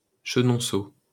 Chenonceaux (French pronunciation: [ʃənɔ̃so]